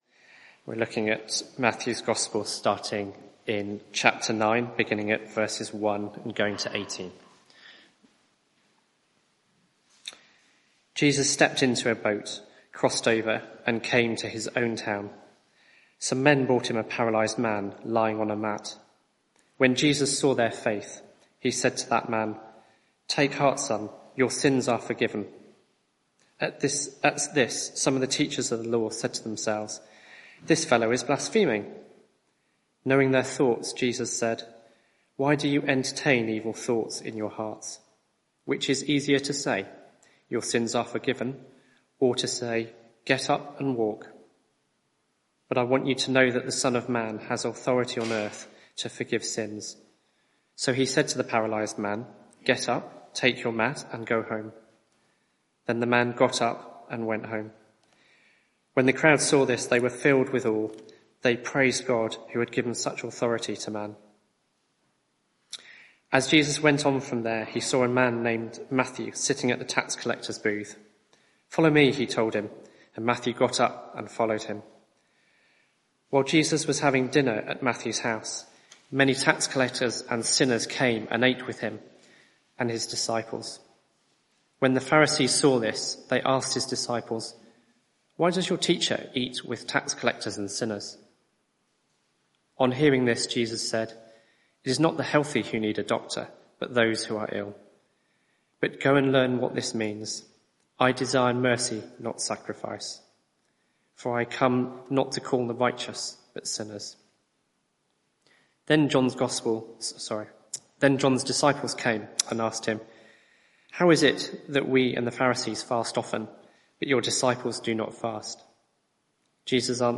Media for 6:30pm Service on Sun 05th May 2024 18:30 Speaker
Passage: Matthew 9:1-17 Series: Jesus confronts the world Theme: Jesus' mission Sermon (audio)